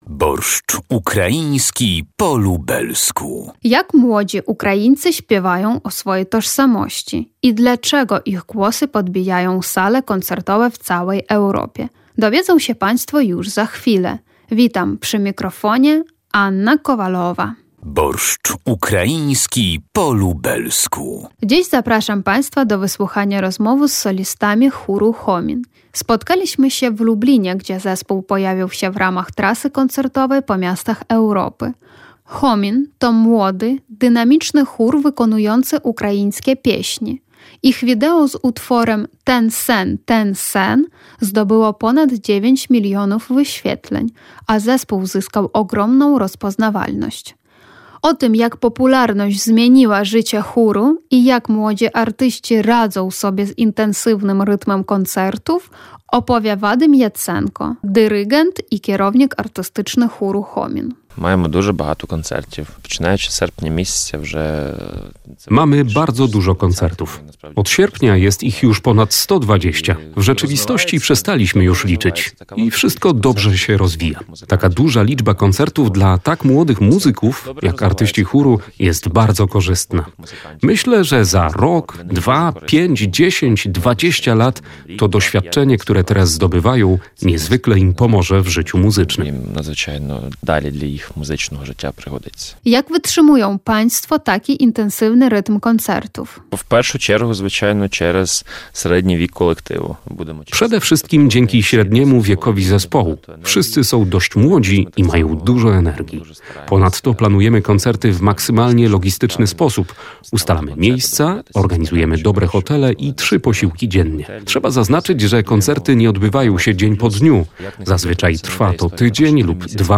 Dziś zapraszam Państwa do wysłuchania rozmowy z solistami chóru „Homin”. Spotkaliśmy się w Lublinie, gdzie zespół pojawił się w ramach trasy koncertowej po miastach Europy. „Homin” to młody, dynamiczny chór wykonujący ukraińskie pieśni.